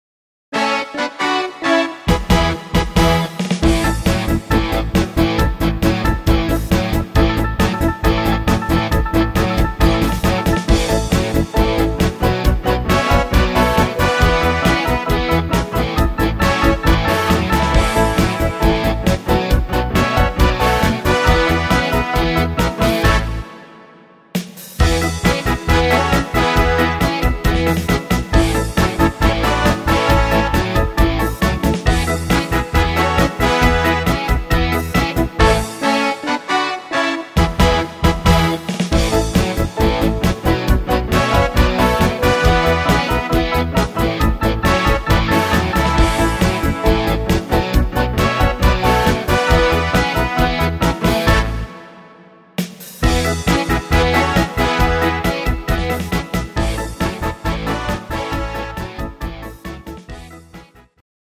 Playback abmischen  Playbacks selbst abmischen!
Rhythmus  60-er Beat
Art  Oldies, Englisch, Pop